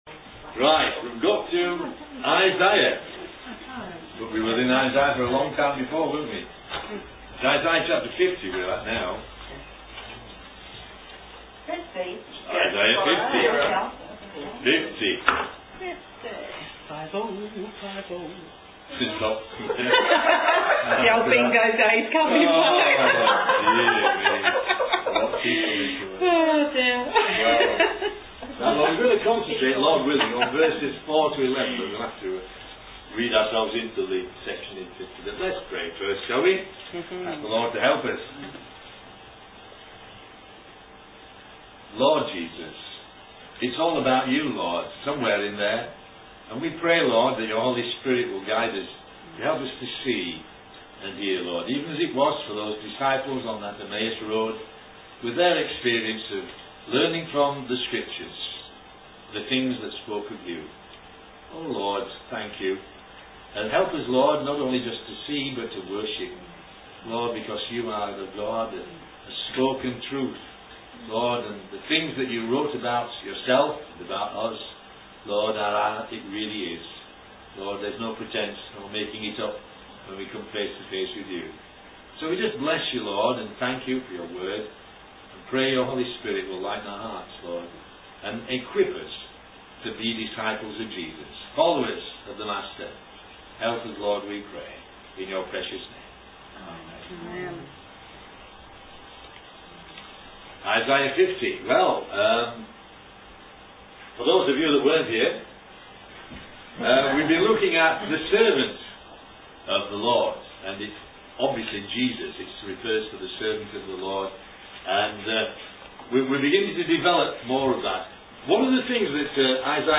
Home page These messages are mostly audio files recorded during our Sunday Evening Fellowship bible studies. They are more of a discussion format with comments from others present as well as the speaker. As the title ‘Christology’ suggests, the messages cover quite a few of the Messianic passages found in the Old Testament scriptures.